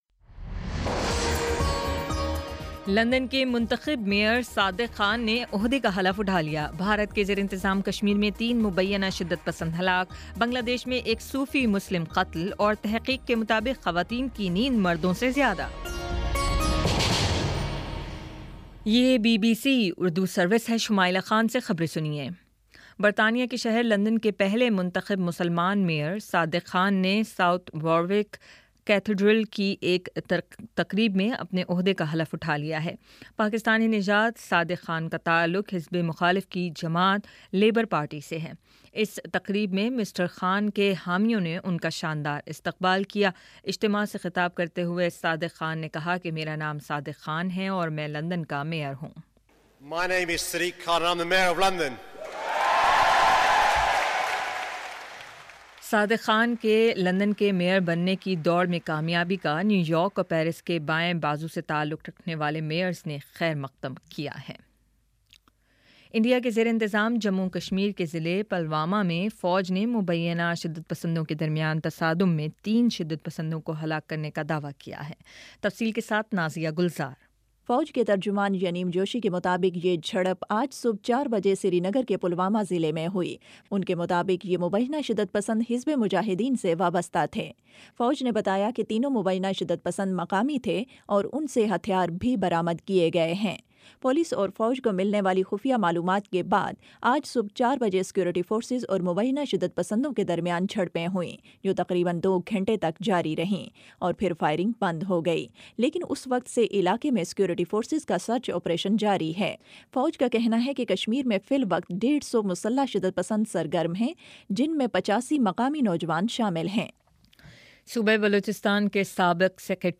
مئی 07 : شام چھ بجے کا نیوز بُلیٹن